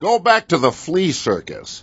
gutterball-3/Gutterball 3/Commentators/Baxter/wack_gotothefleacircus.wav at main